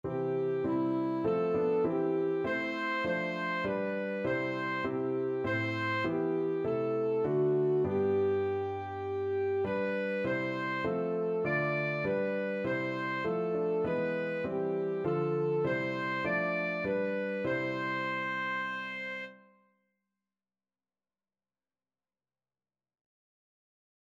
Christian
Alto Saxophone
4/4 (View more 4/4 Music)
E5-D6
Classical (View more Classical Saxophone Music)